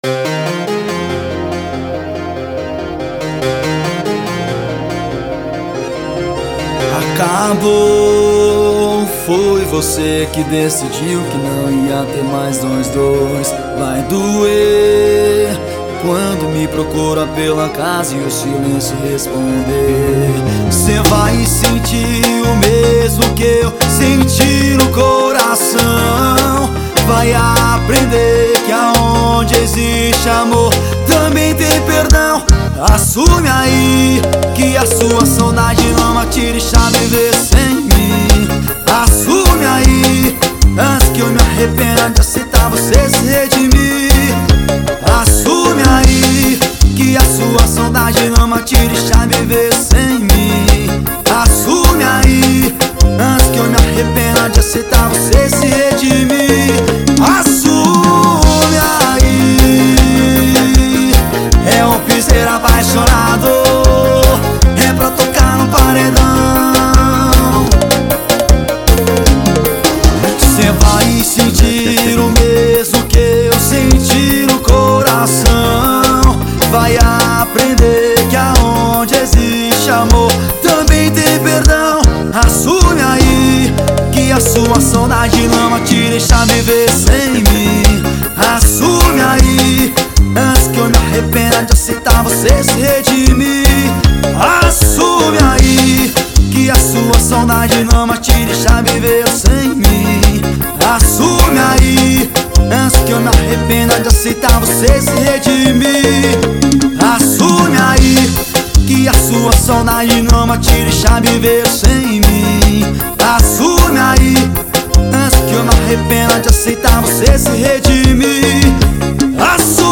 EstiloForró